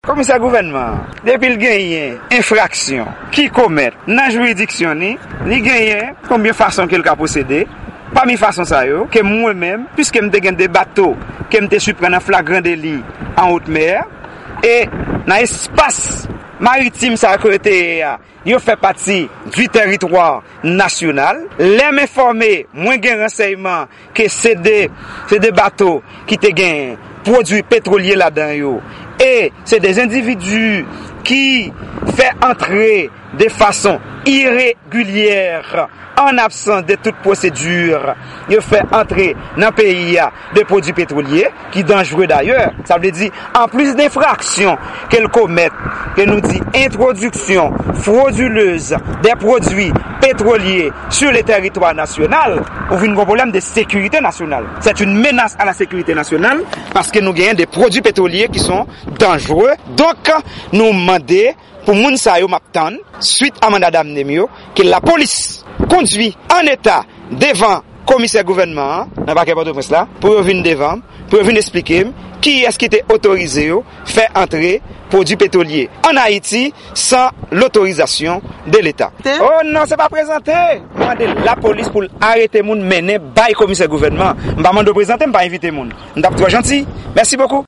Deklarasyon Komisè Gouvènman Pòtoprens la, Mèt Clamé Ocname Daméus, sou Dosye Gaz Ilegal la.